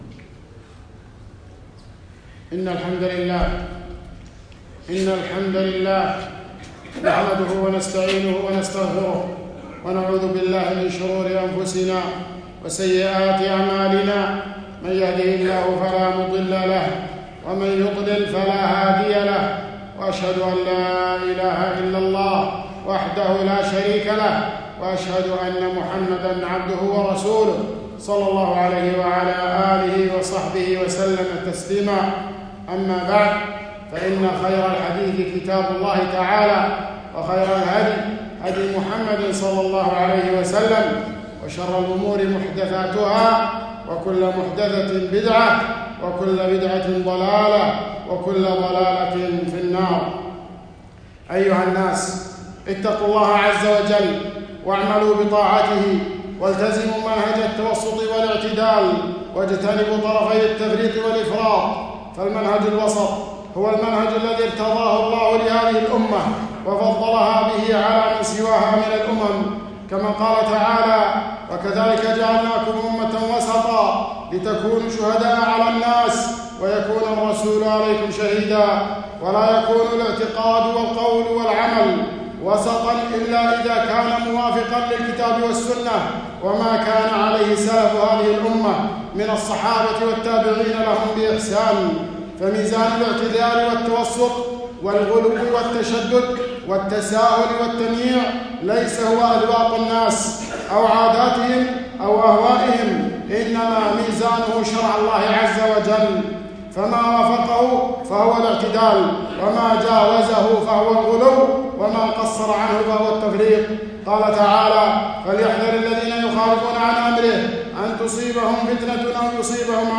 خطبة - مدح النبي ﷺ بين أهل السنة وأهل الغلو